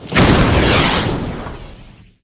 RedRainPowerHit.wav